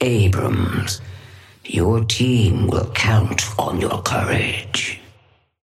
Sapphire Flame voice line - Abrams, your team will count on your courage.
Patron_female_ally_atlas_start_05.mp3